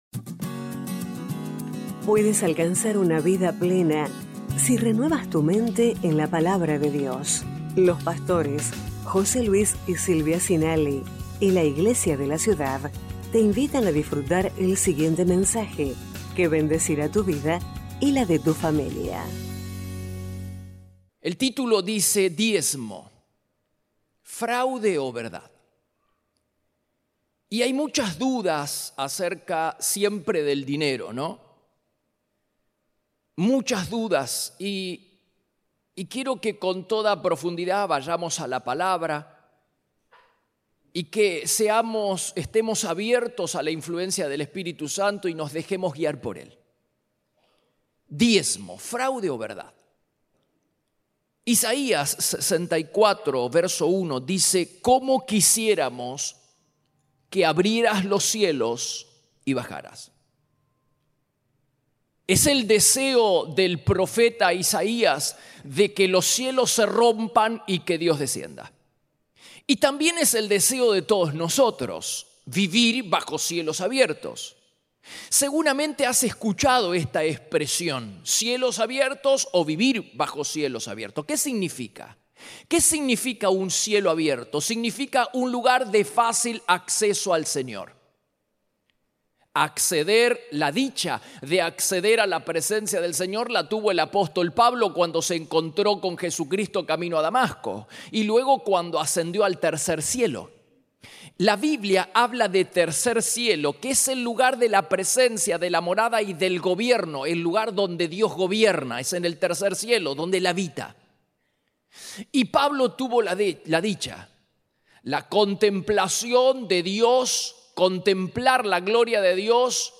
Iglesia de la Ciudad - Mensajes / Diezmo, ¿fraude o verdad? 9/7/2023 #1256